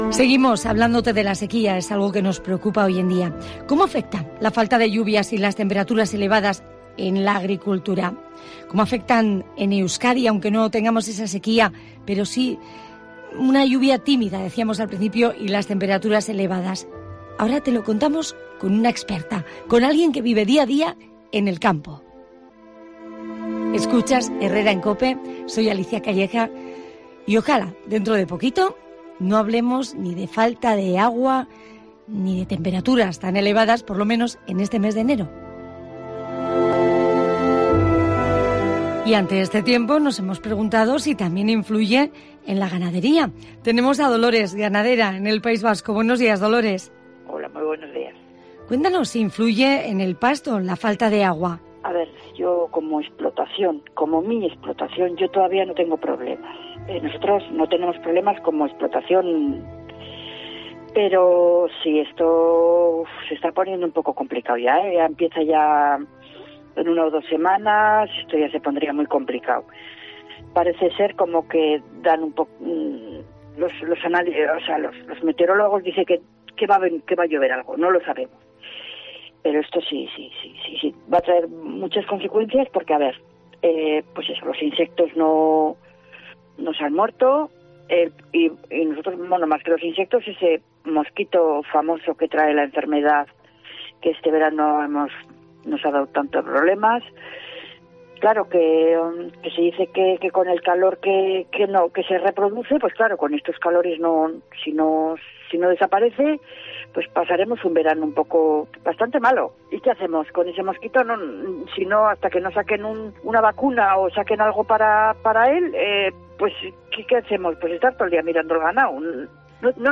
COPE Euskadi analiza con bodegueros y ganaderos las consecuencias de la falta de lluvia